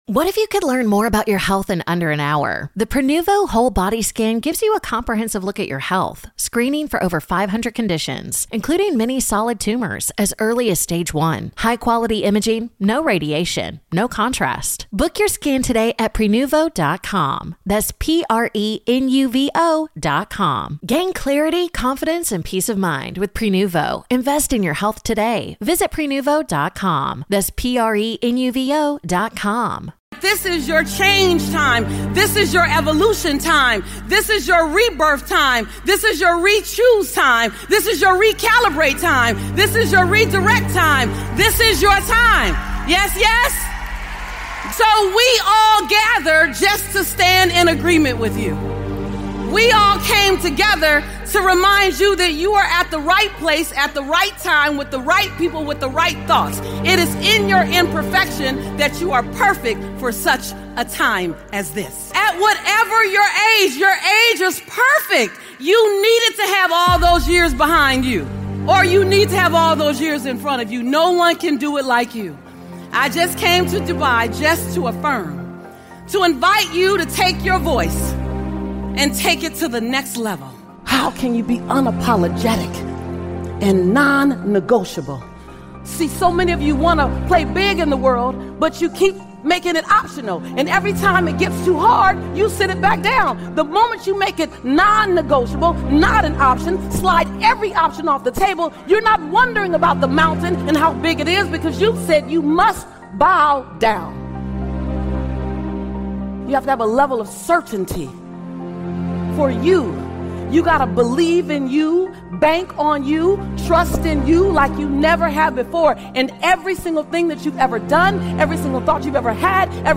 Lisa Nichols - This is your time motivational speech
With her signature blend of raw honesty, spiritual fire, and unshakeable belief in human potential, Lisa reminds you that no matter your past pain, setbacks, or current circumstances, the moment you decide to claim your power is the moment everything shifts.